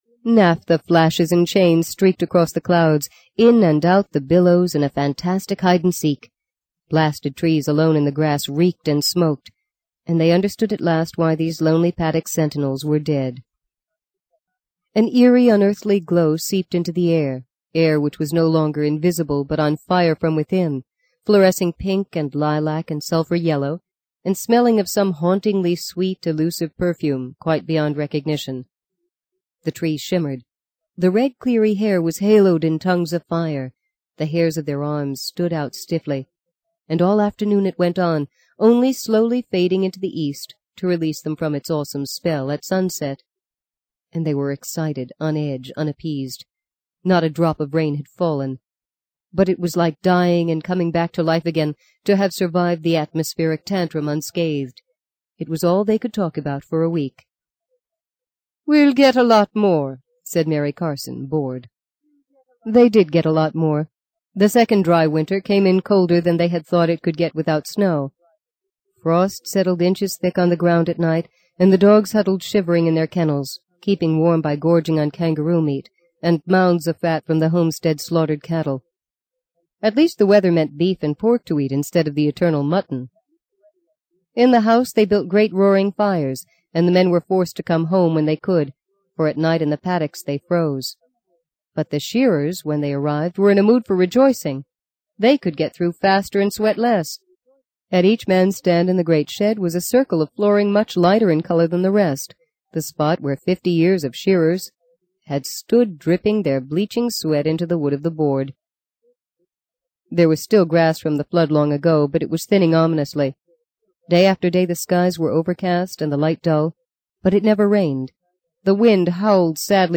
在线英语听力室【荆棘鸟】第六章 09的听力文件下载,荆棘鸟—双语有声读物—听力教程—英语听力—在线英语听力室